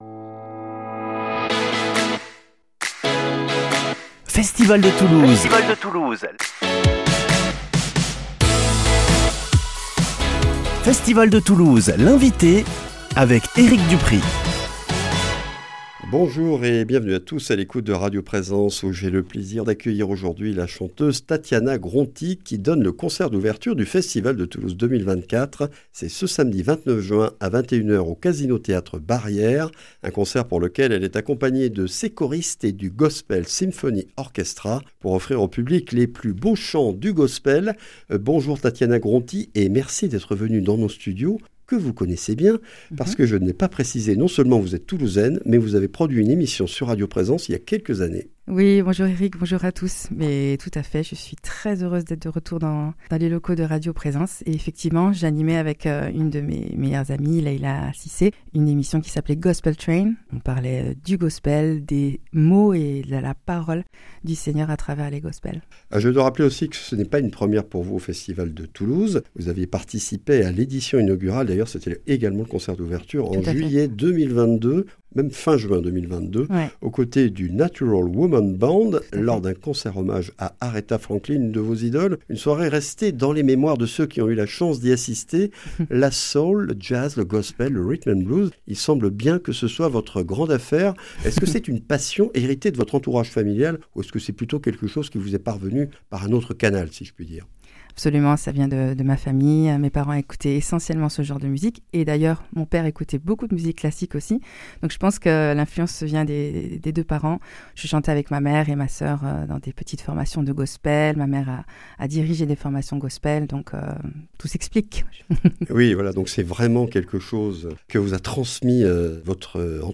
Entretien avec une vraie dingue de gospel.